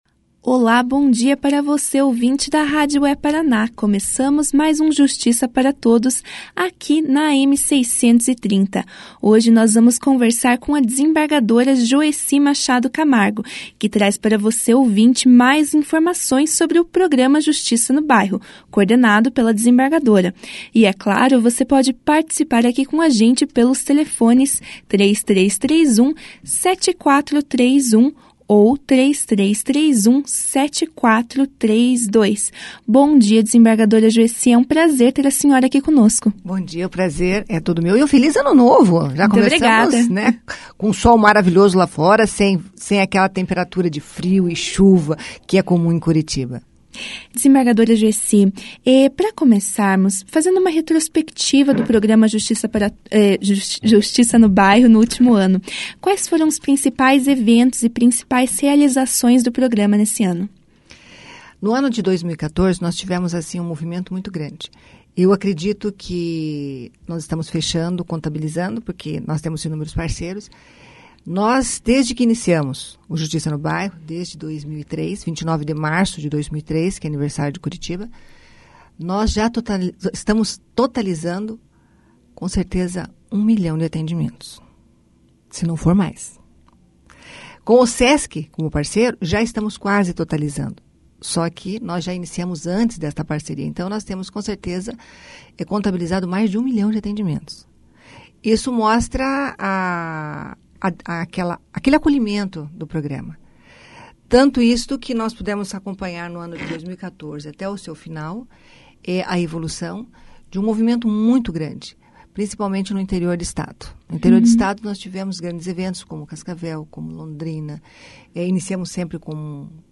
Desembargadora Joeci Machado Camargo fala sobre o programa Justiça no Bairro
A desembargadora Joeci Machado Camargo, coordenadora do Justiça no Bairro, explicou para os ouvintes da É-Paraná o funcionamento do programa e suas parcerias. Durante a entrevista ela fez uma retrospectiva do Justiça no Bairro do ano de 2014 citando os principais eventos e realizações.